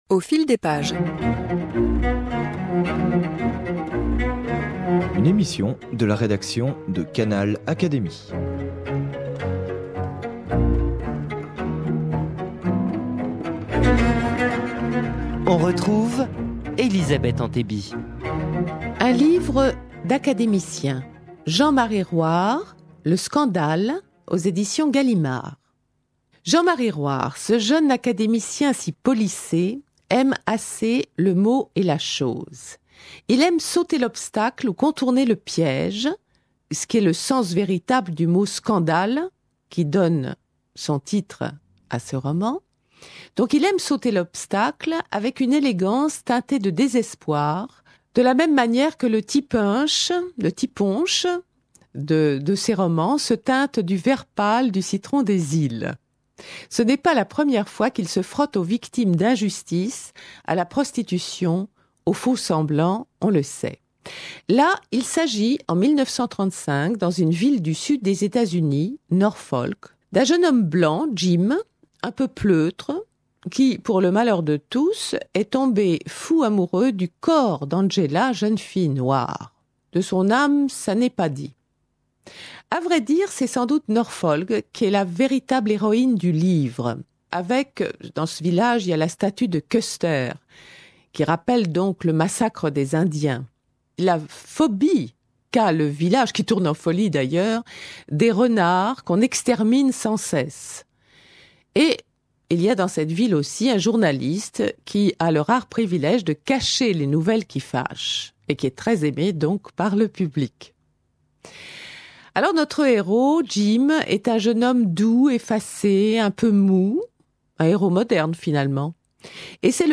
Une chronique littéraire